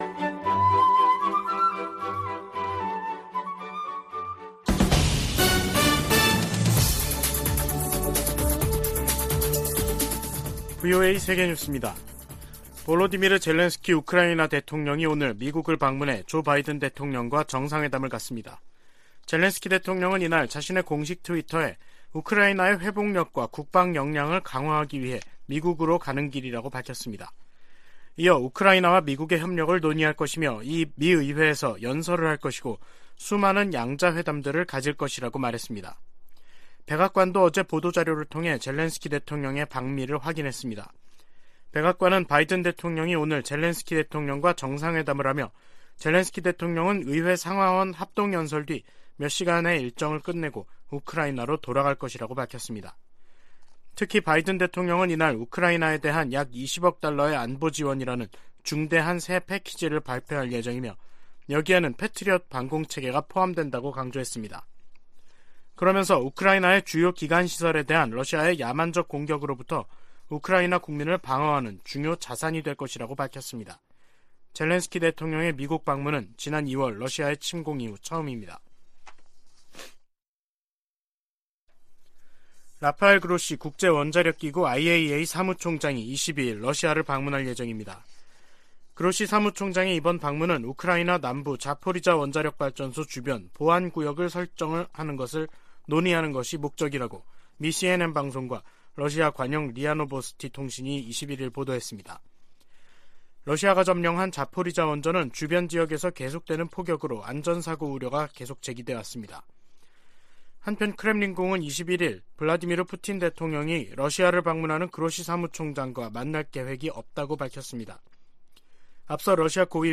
VOA 한국어 간판 뉴스 프로그램 '뉴스 투데이', 2022년 12월 21일 3부 방송입니다. 미 국무부는 북한의 7차 핵실험이 정치적 결단만 남았다며, 이를 강행시 추가 조치를 취하겠다고 밝혔습니다. 미국 국방부가 북한의 도발 억제를 위해 역내 동맹들과 긴밀하게 협력할 것이라고 거듭 밝혔습니다.